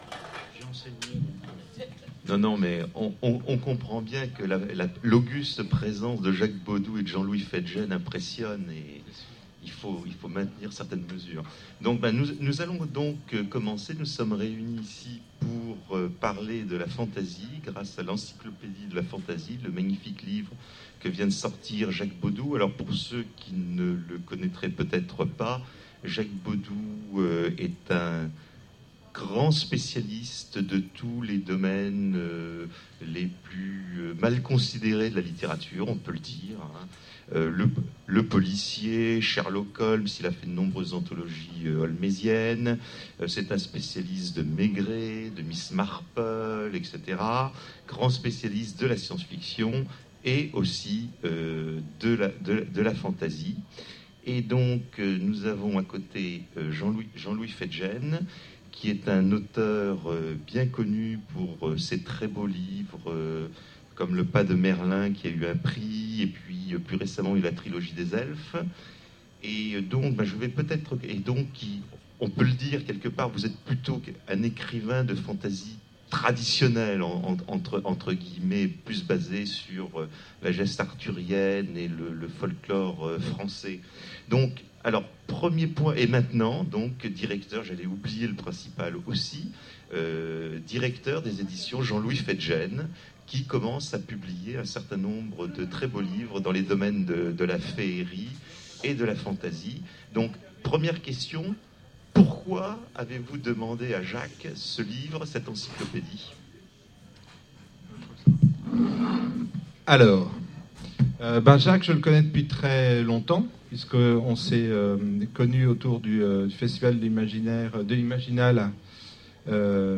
Conférence : L'encyclopédie de la fantasy (Zone Franche 2010)
Voici l'enregistrement de la conférence L'encyclopédie de la fantasy lors du festival Zone Franche de Bagneux en février 2010